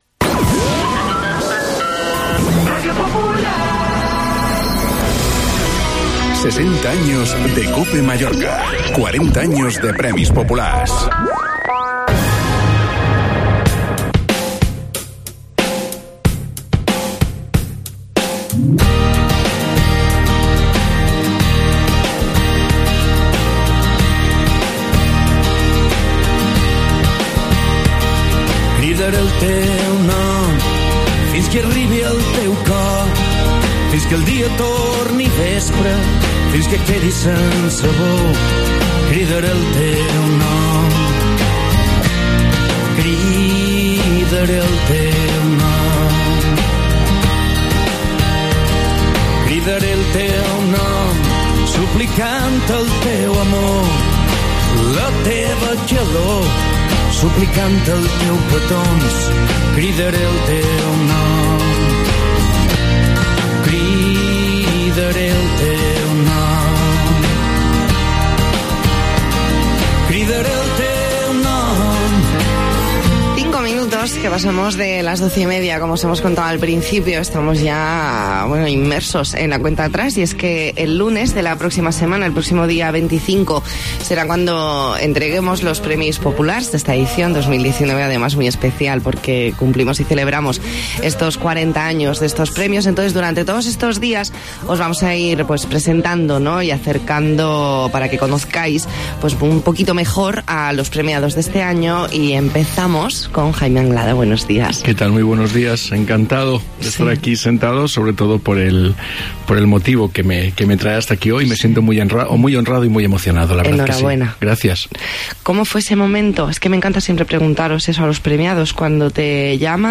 Entrevista en La Mañana en COPE Más Mallorca, lunes 18 de noviembre de 2019.